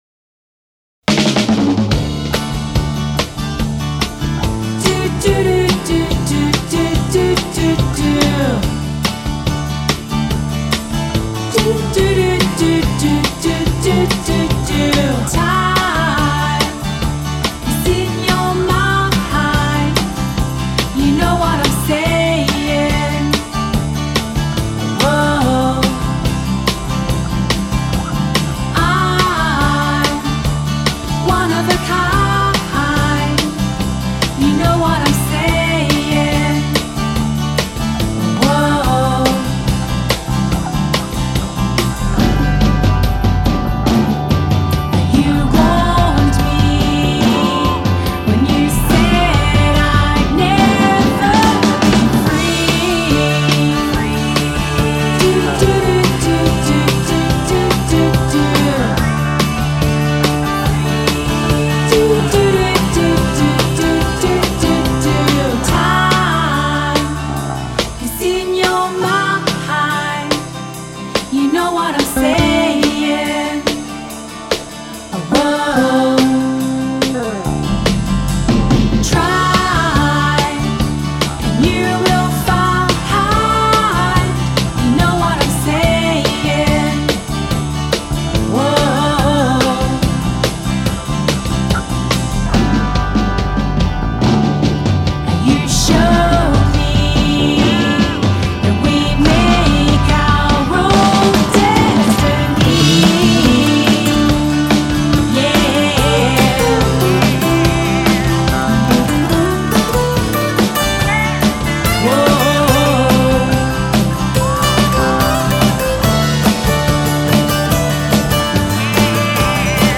“poetic” and “quirky”